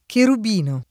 kerub&no] s. m. — sim. il pers. m. Cherubino, i cogn. Cherubin [kerub&n], Cherubina, Cherubini, Cherubino, il top. Cherubine (Ven.) — poet. cherubo [